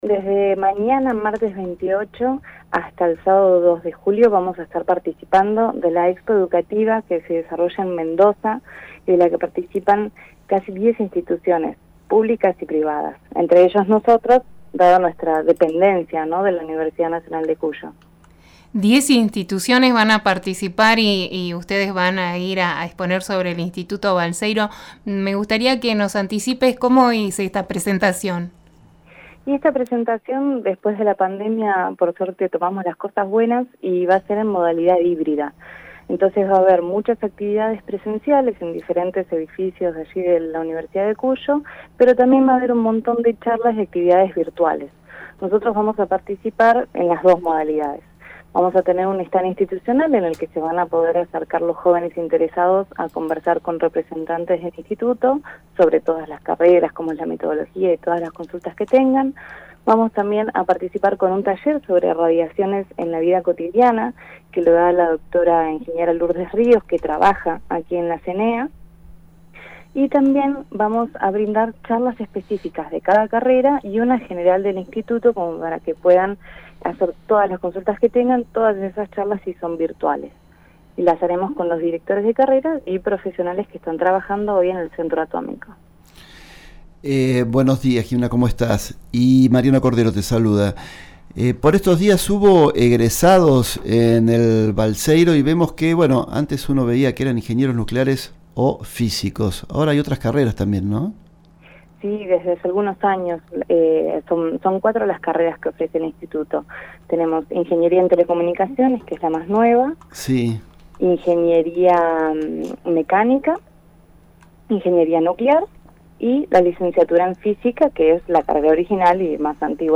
fue entrevistada por Radio Nacional Bariloche.